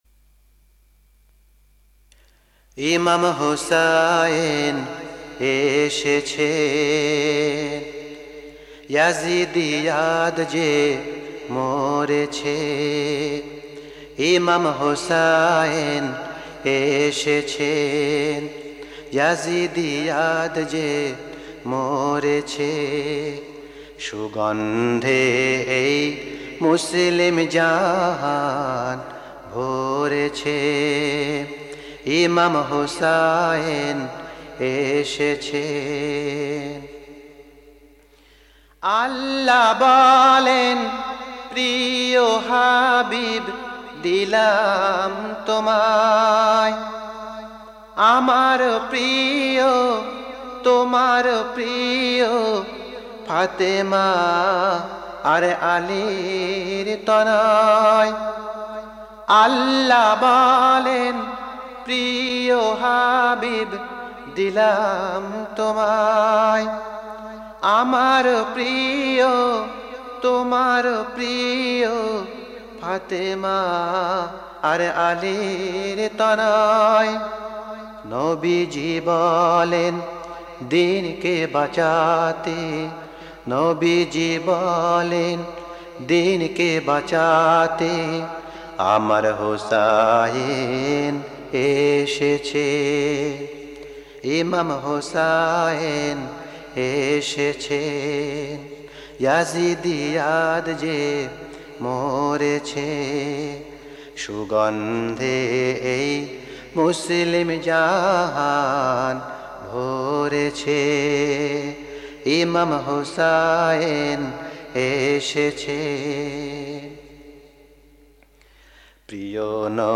সংগ্রহ তালিকা মার্সিয়া ইমাম আল-হাসনাইন (আঃ) এর নেটওয়ার্কে